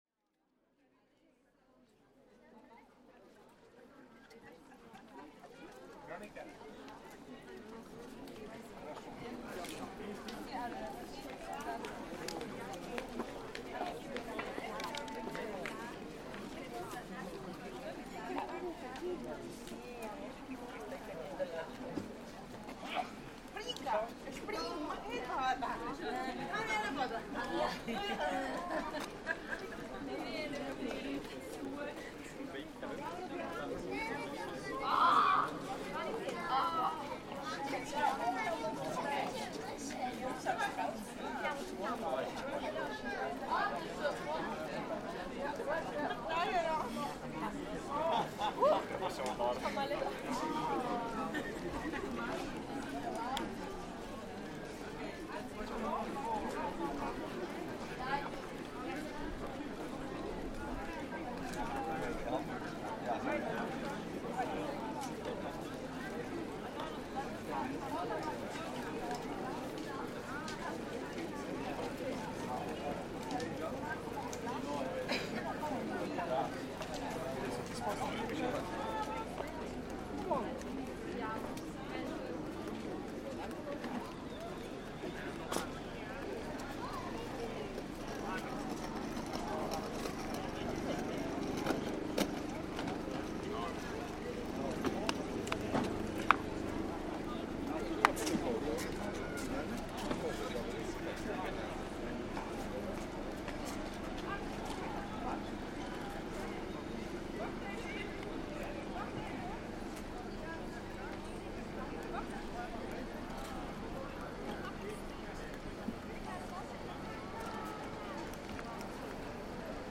Soundwalk through Piazza del Duomo, Pisa
This soundwalk takes the listener right up to the foot of one of the world's most iconic structures, the Leaning Tower of Pisa.